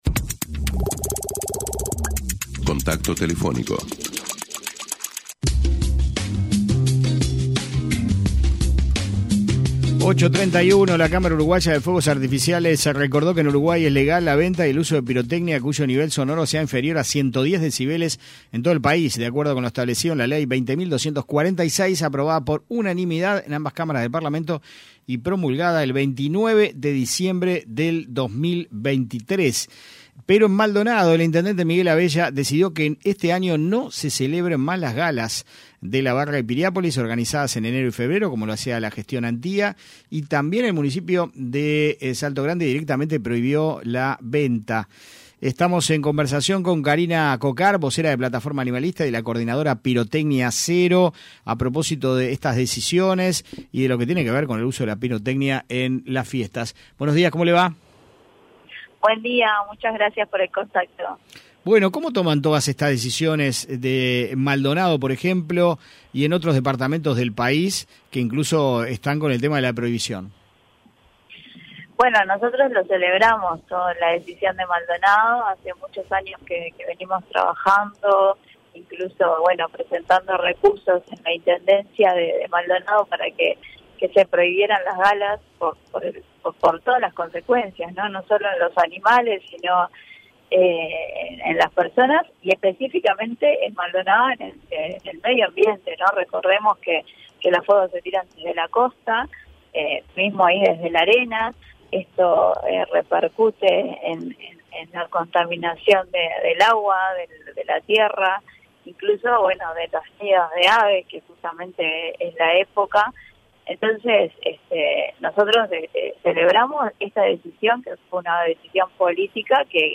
en diálogo con 970 Noticias